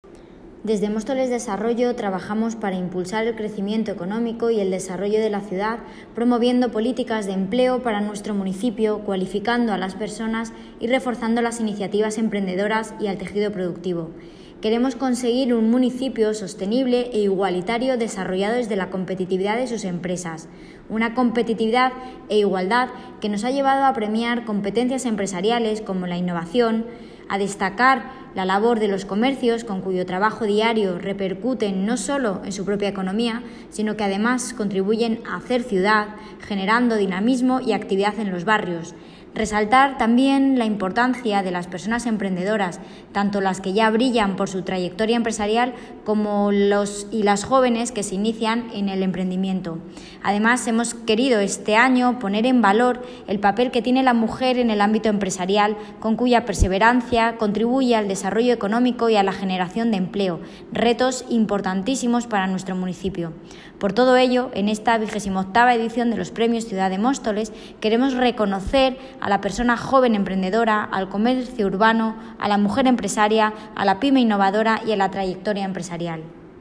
Audio - Jessica Antolín (Concejala Desarrollo Económico, Empleo y Nuevas Tecnologías) Sobre Premios Ciudad de Móstoles